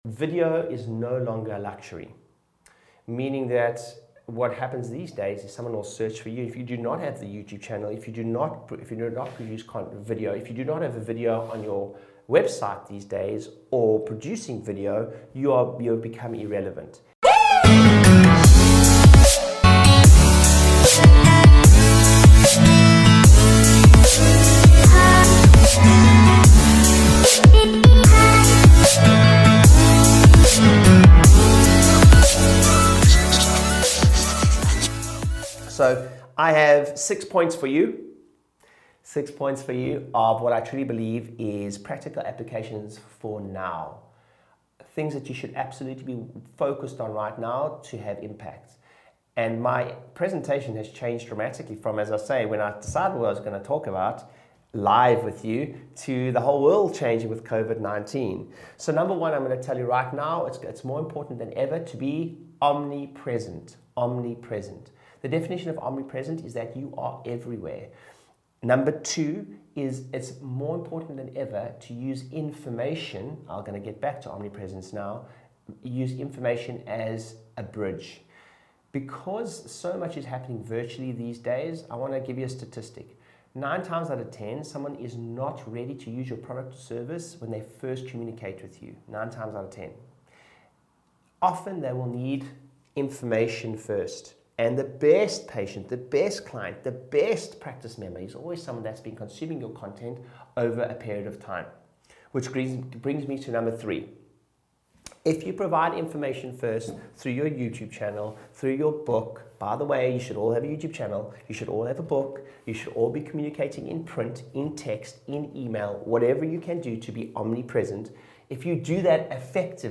Check out this segment from my virtual presentation for The Wave.